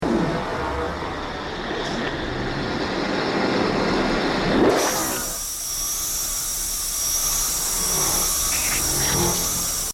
Low Buzzing Whoosh